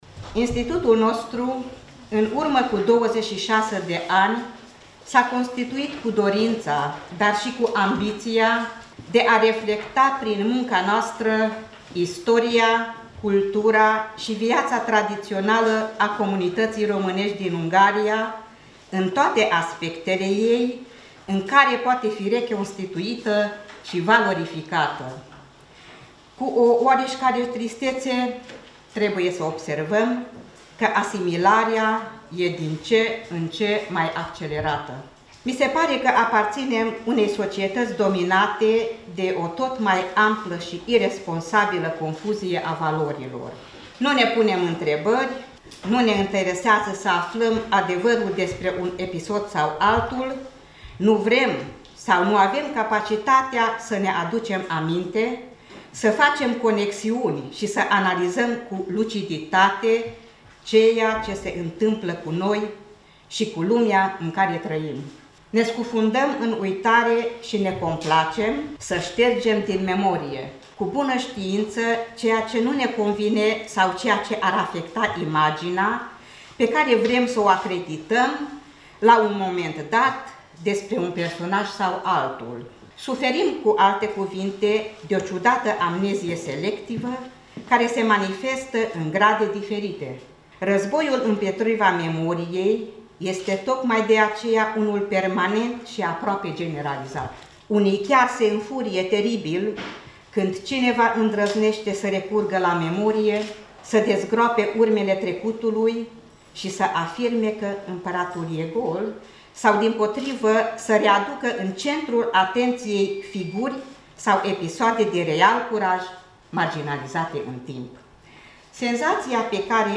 Întâlnirea anuală a cercetătorilor români din Ungaria, simpozionul ştiinţific, de această dată a fost organizat doar o singură zi, pe 9 noiembrie, la Jula, în sediul Uniunii Culturale.